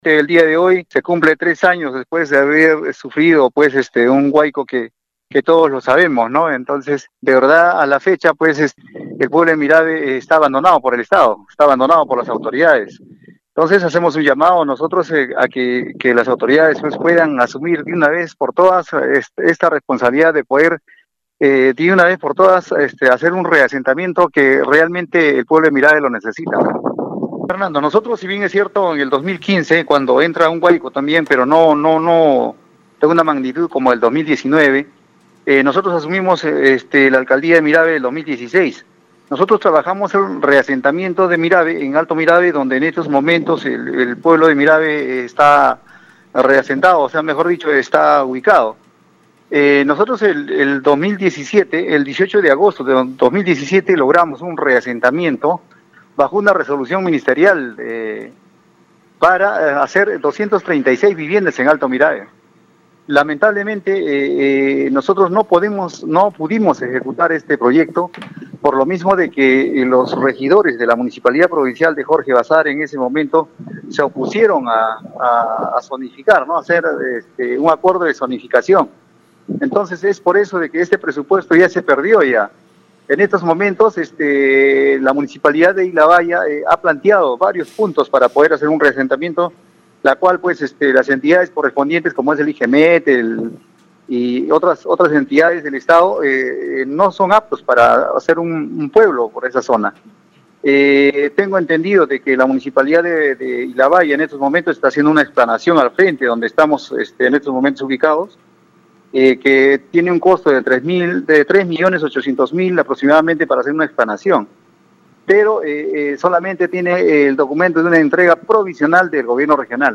Pero «a la fecha el pueblo de Mirave está abandonado por el Estado, está abandono por las autoridades», aseveró Augusto Guzmán Meléndez, exalcalde de Mirave.
guzman-melendez-ex-alcalde-de-mirave.mp3